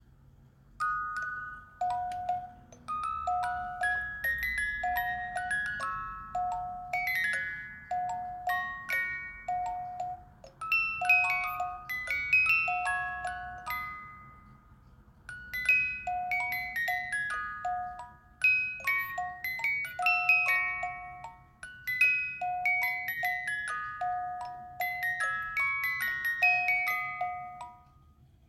Assiette en bois avec boite à musique
Lorsque vous soulevez l’assiette, une mélodie se déclenche automatiquement.